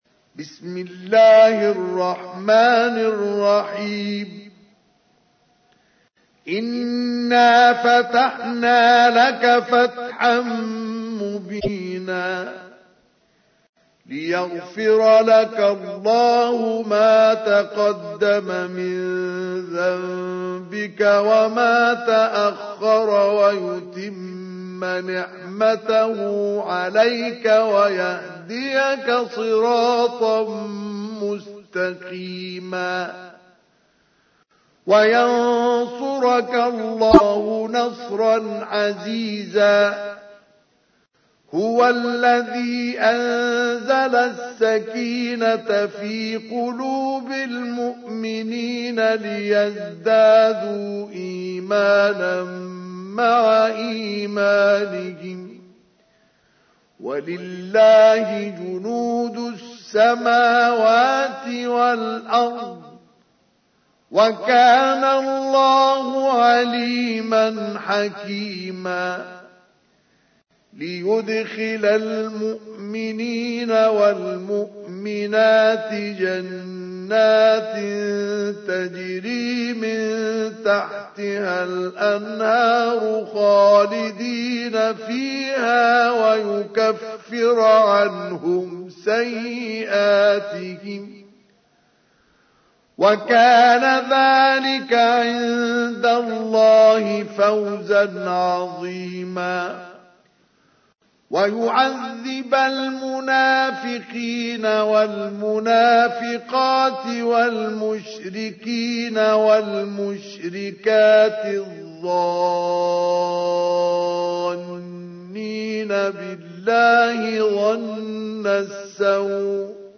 تحميل : 48. سورة الفتح / القارئ مصطفى اسماعيل / القرآن الكريم / موقع يا حسين